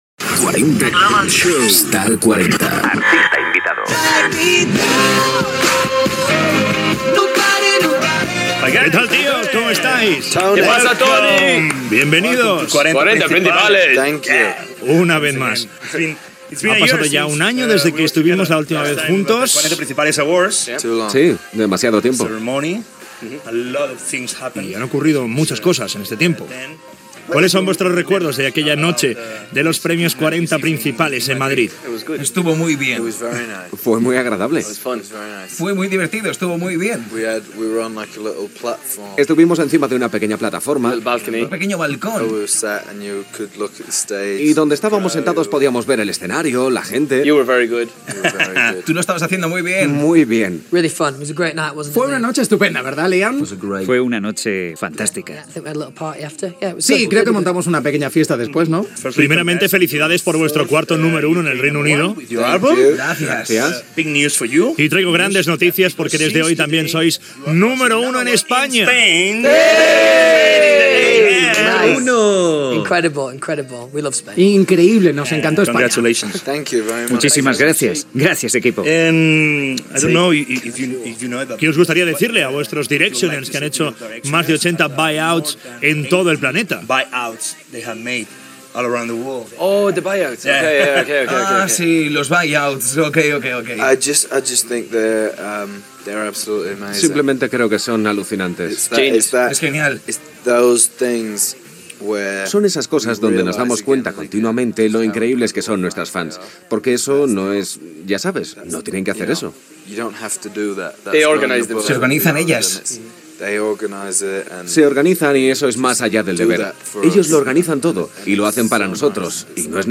Presentació i entrevista als integrants del grup One Direction
Musical